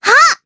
Lilia-Vox_Attack1_jp.wav